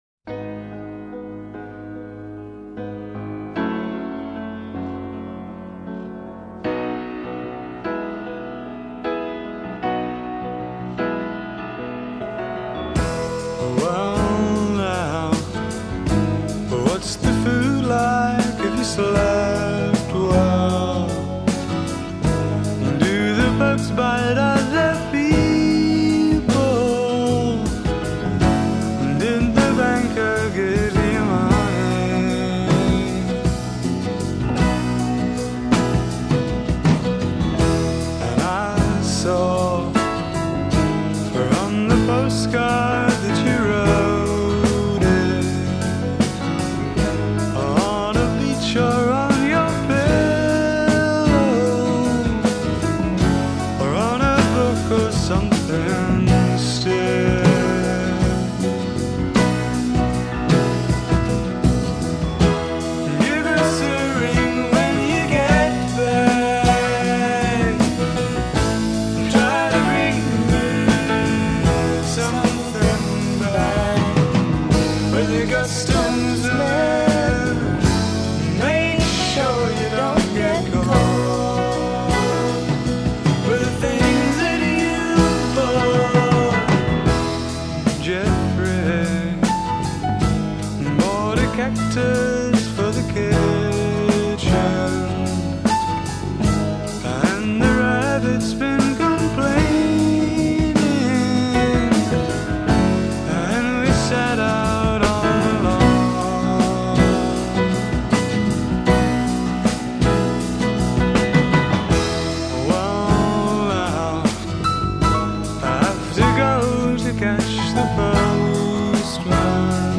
has more of the same but with lots more jazz playing
melancholy vacation anthem